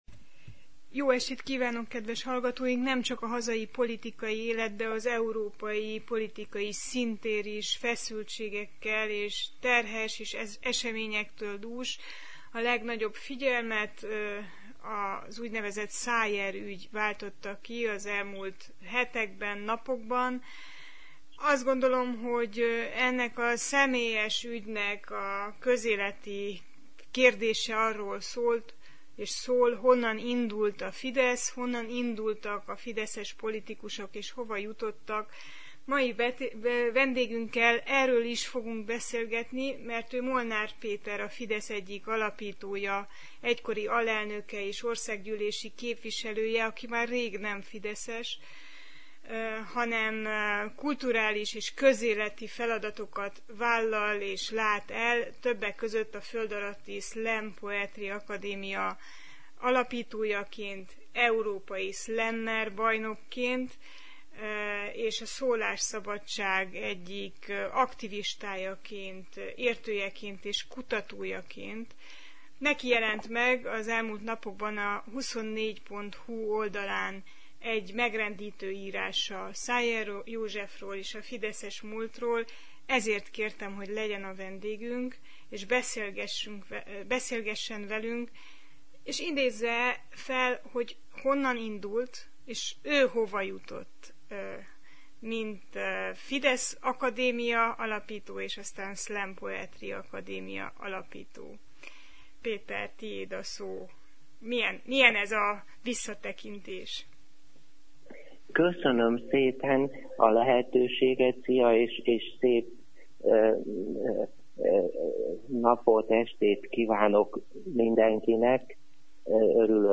A Szájer botrány kapcsán beszélgettünk generációjának politikai álmairól, és azok megvalósulásáról.